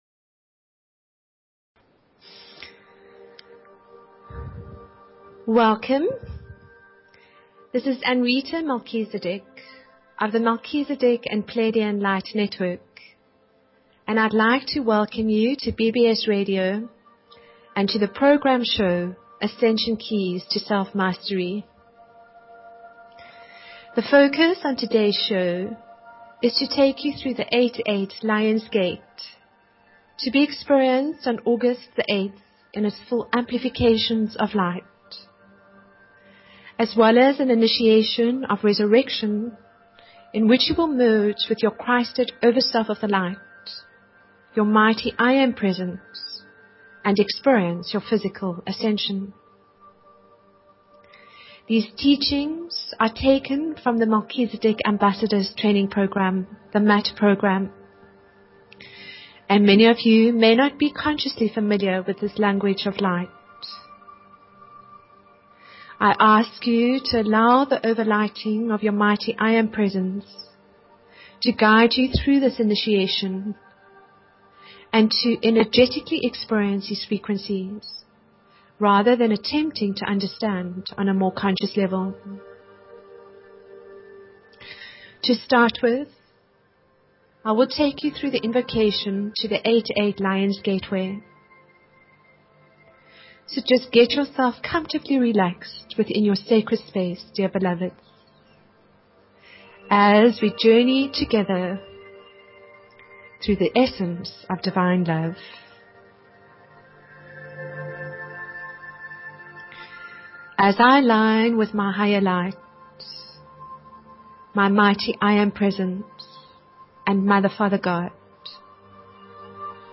Talk Show Episode, Audio Podcast, Ascension_Keys_to_Self_Mastery and Courtesy of BBS Radio on , show guests , about , categorized as
A beautiful channeling through these merging timelines.